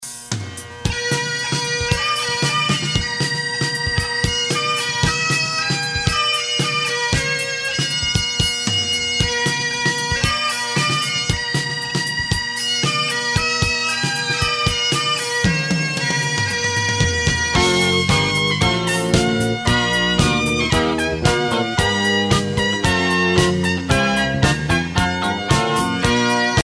circonstance : militaire
Pièce musicale éditée